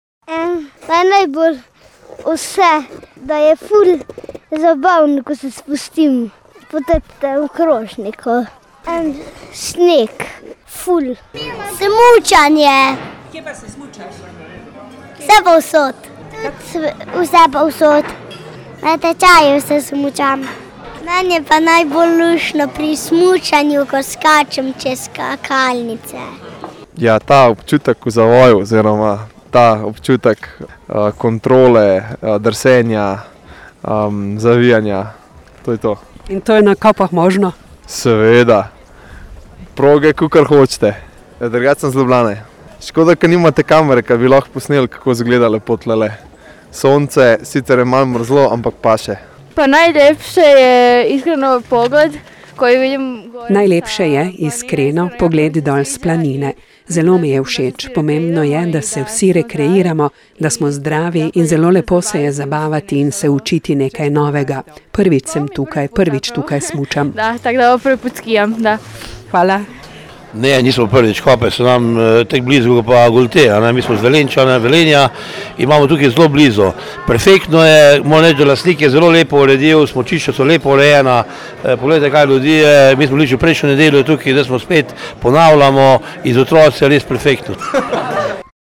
da pa so tudi vse proge vrh Pohorja čudovite, pa smo slišali tudi od smučarjev:
IZJAVE SANKARJI SMUCARJI_1.mp3